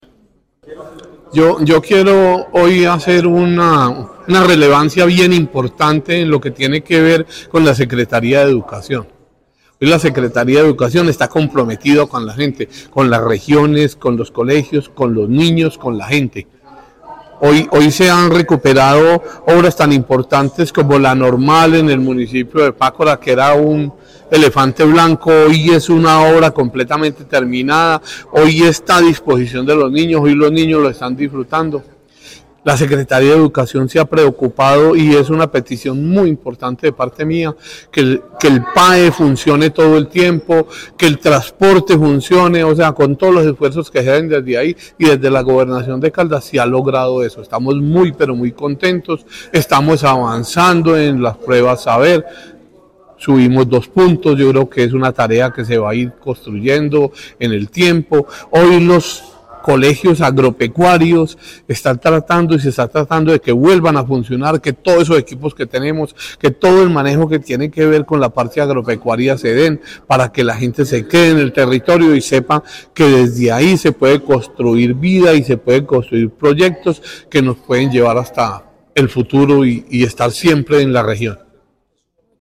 Diputado de Caldas, Carlos Arango.
Diputado-Carlos-Arango-informe-Sec.-Educacion.mp3